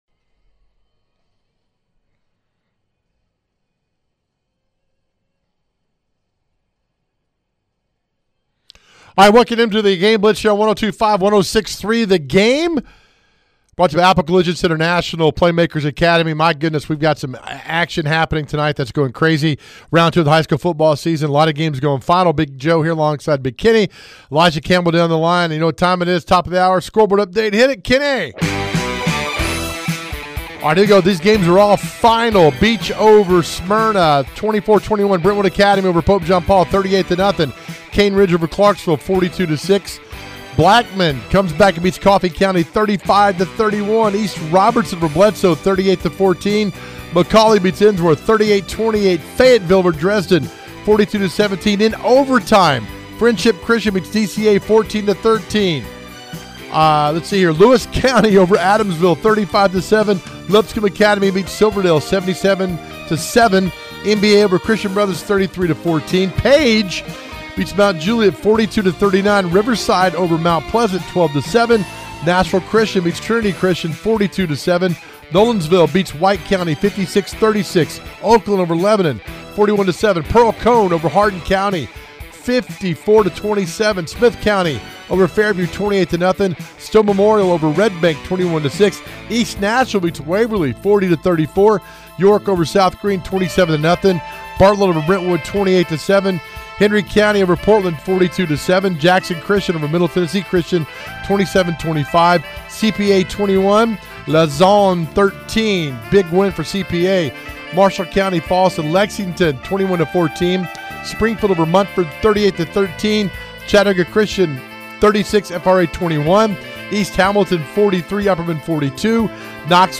We are in round two of TN High School Football and we cover it all with head coach and reporter interviews!